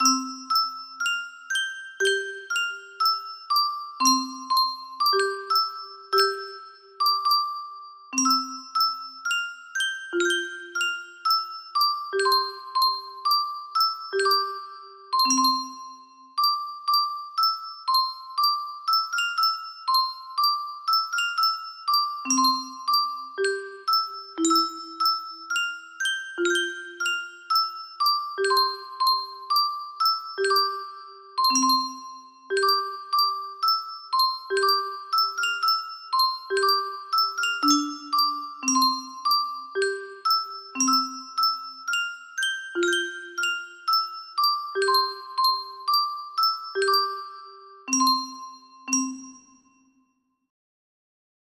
Himno a la alegría (Bethoven) music box melody